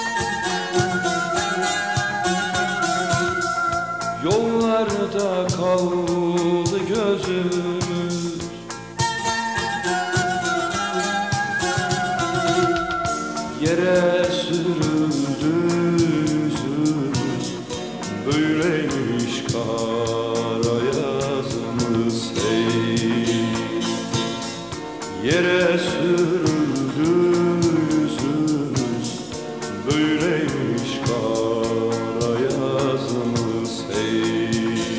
Halk